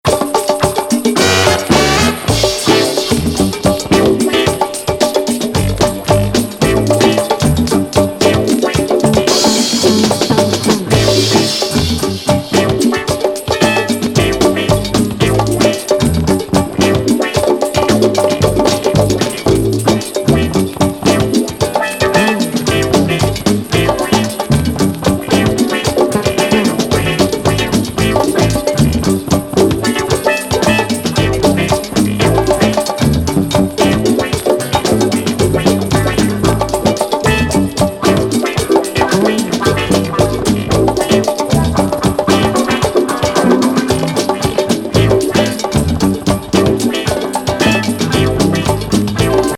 ボンゴ・トライバル+チャカポコなマイナー・インスト・ディスコ・ファンク!